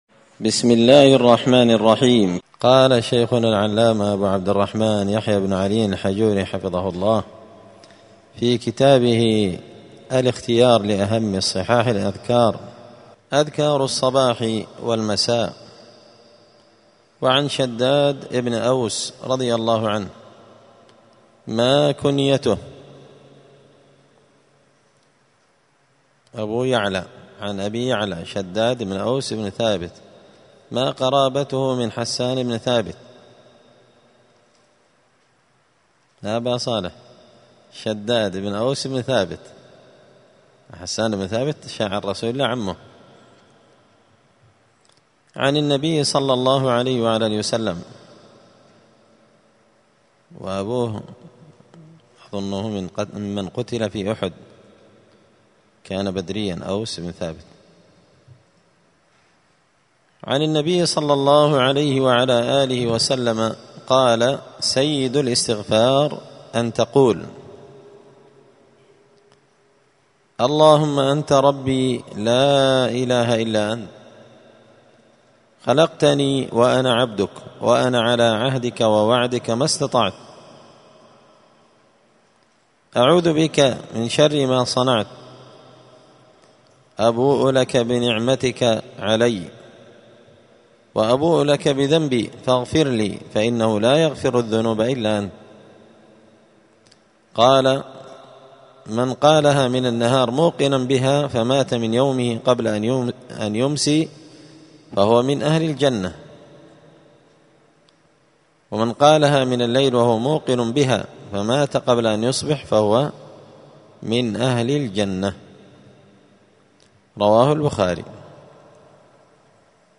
*{الدرس السابع (7) أذكار الصباح والمساء}*